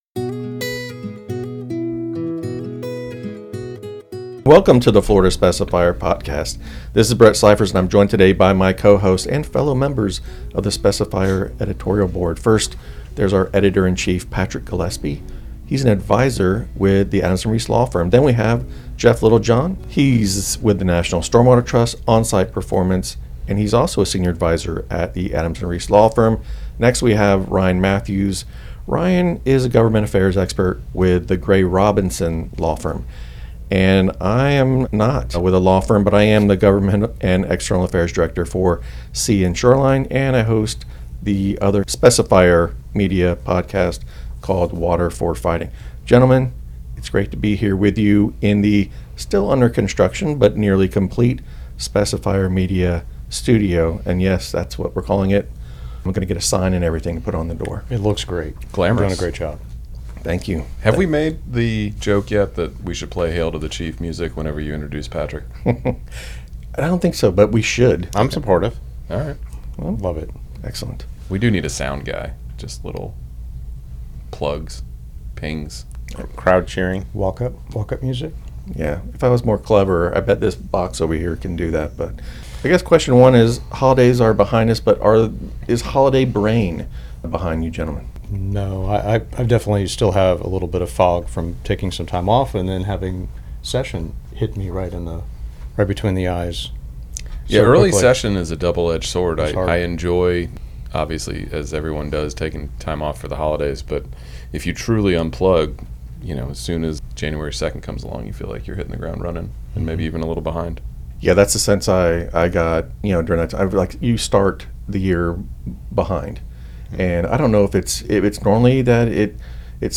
The team sits down for a timely discussion recorded in the newly launched Specifier Media Studio in Tallahassee. As Florida’s legislative session ramps up, the board breaks down early-session dynamics, budget pressures, and the role relationships and personalities play in shaping policy outcomes.